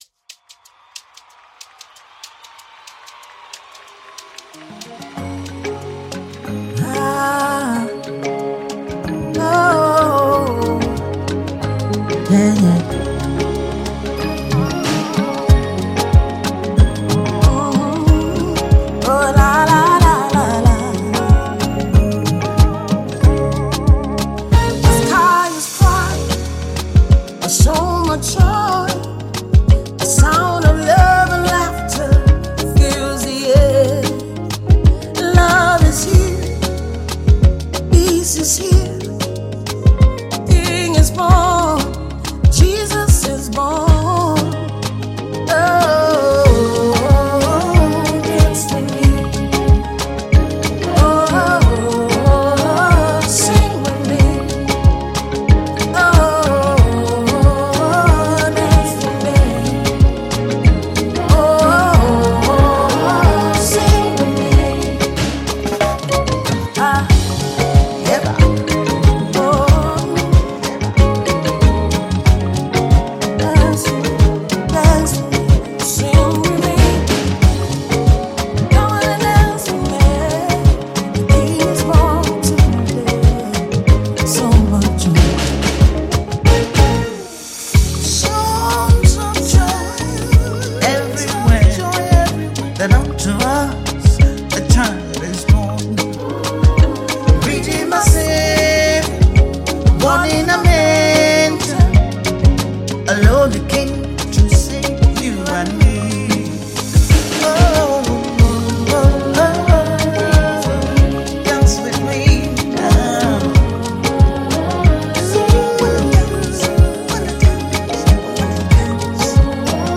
Award-winning Gospel singer and global worship leader
contemporary gospel